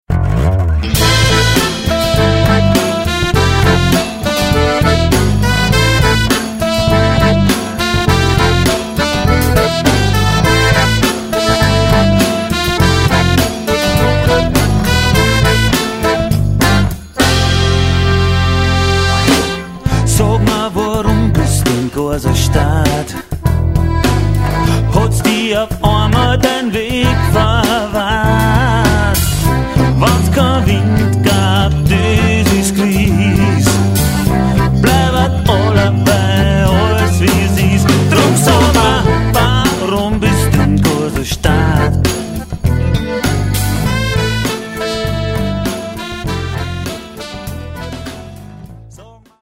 akkordeon und gesang
bass und gesang
gitarren, mandoline, banjo und gesang